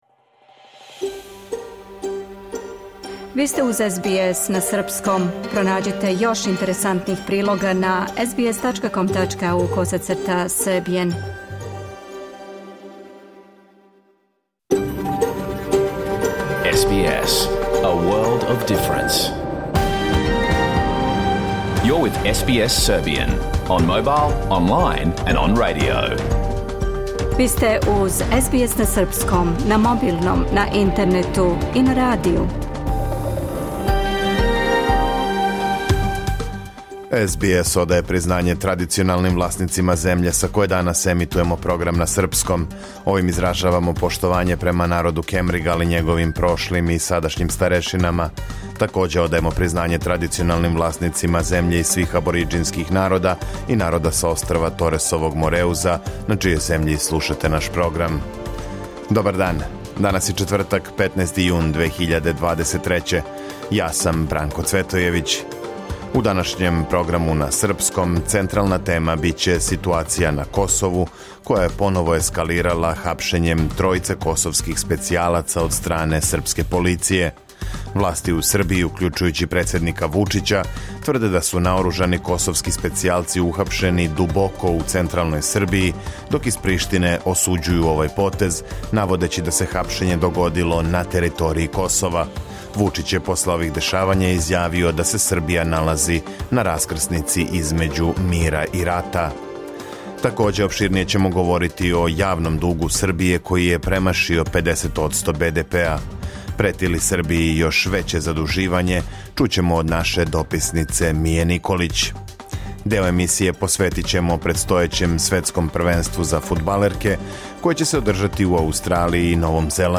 Програм емитован уживо 15. јуна 2023. године
Уколико сте пропустили данашњу емисију, можете је послушати у целини као подкаст, без реклама.